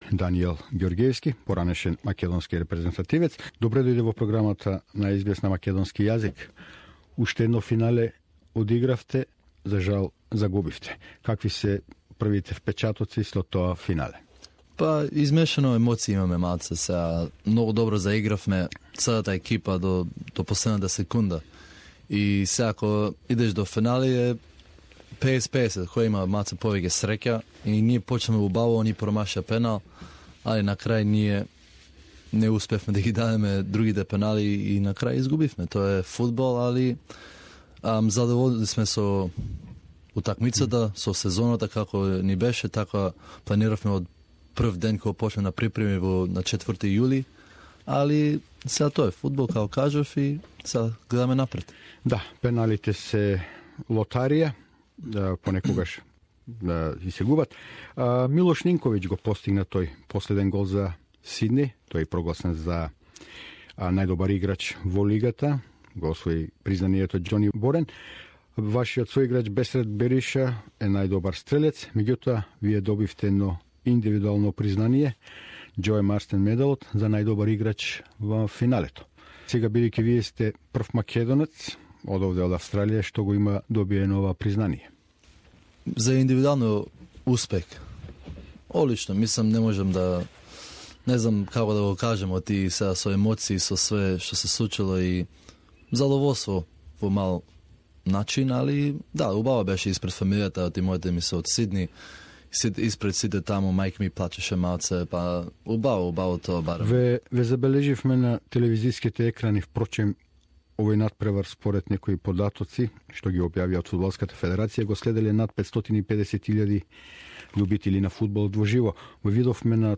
at the Melbourne studios